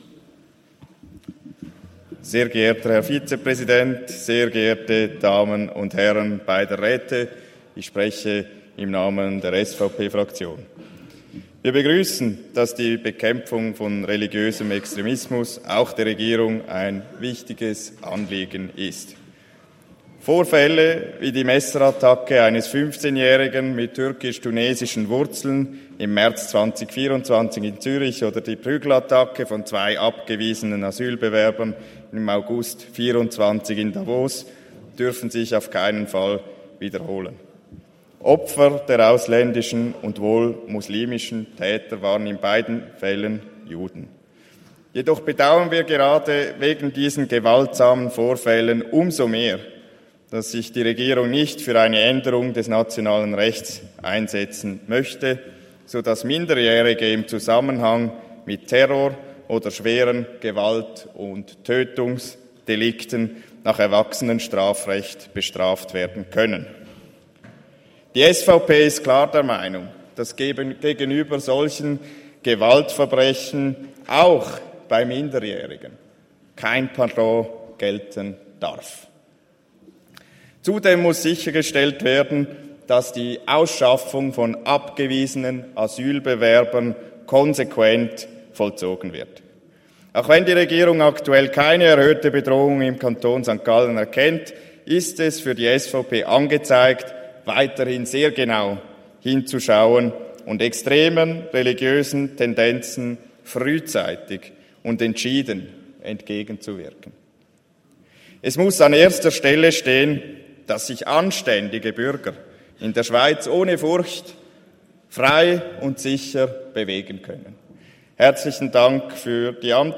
Session des Kantonsrates vom 16. bis 18. September 2024, Herbstsession